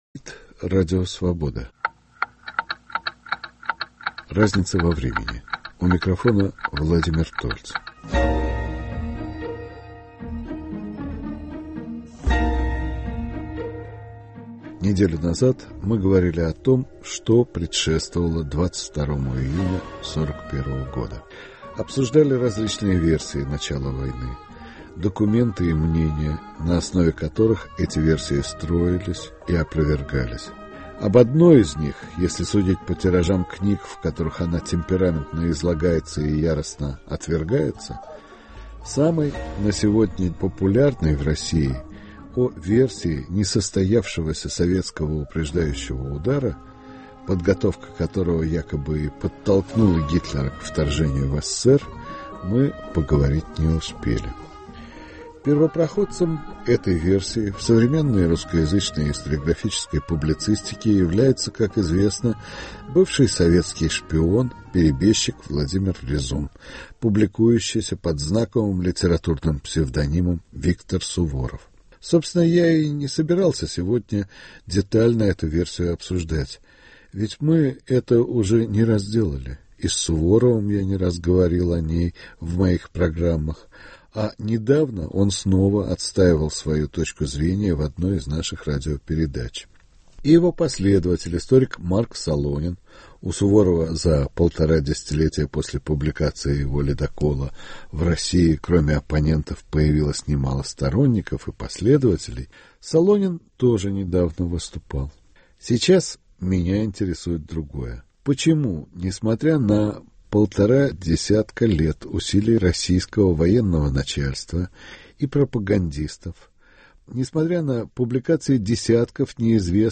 В ней военные, журналисты и историки обсуждают особенности и причины живучести концепции германо-советской войны, авторство которой принадлежит историческому публицисту Виктору Суворову.